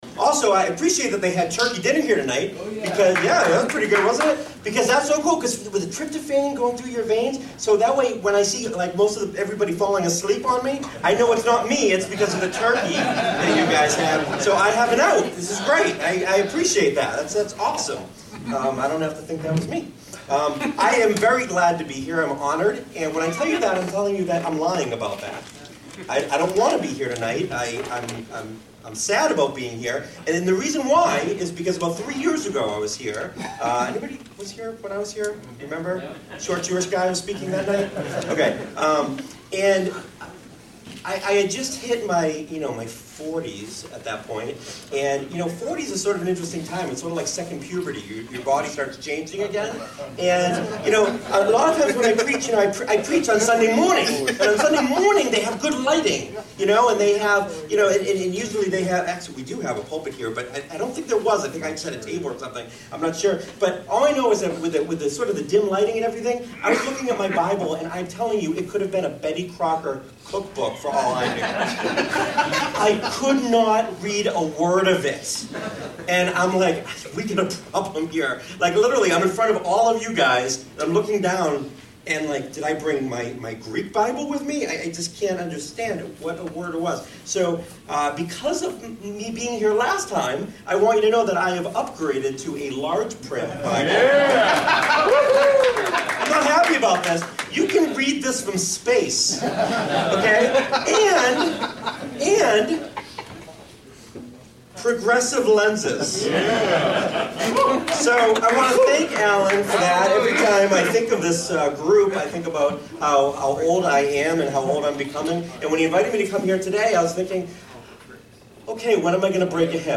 New Sermon – Jesus Is Greater…